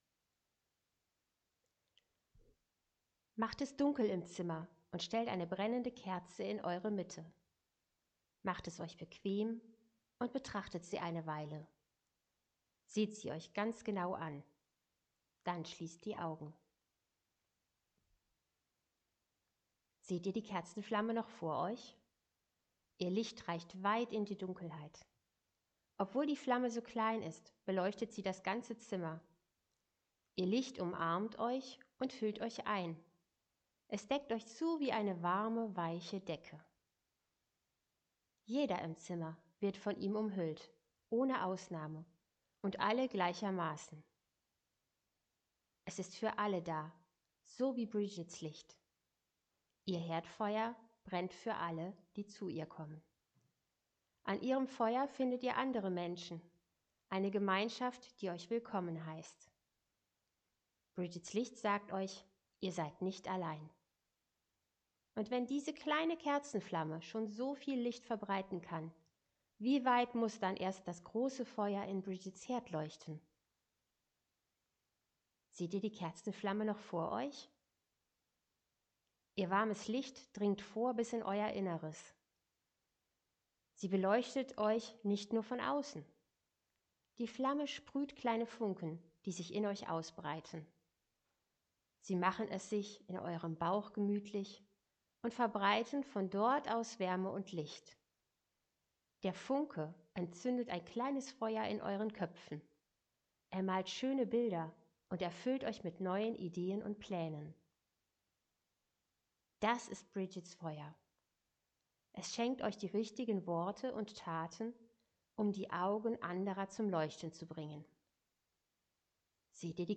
Kindermeditation zu Brighid
Kindermeditation-zu-Brighid.mp3